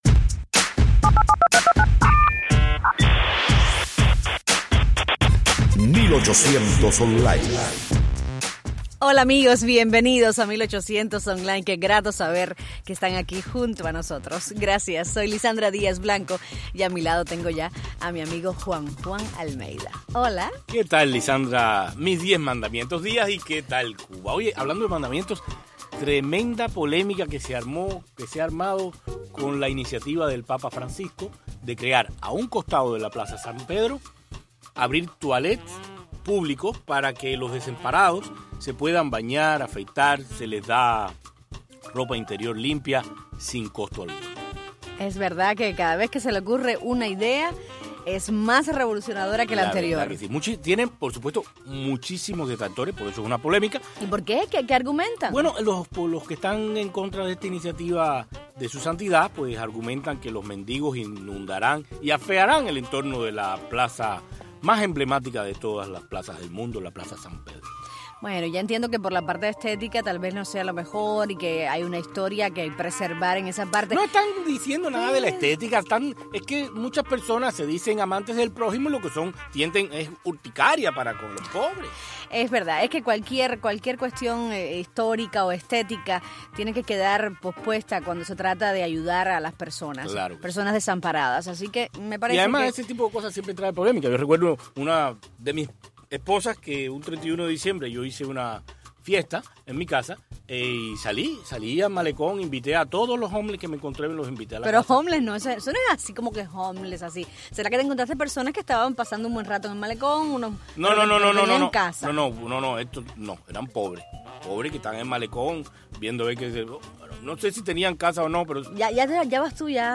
Porque somos puente y no trinchera, es un placer conversar con todos nuestros oyentes.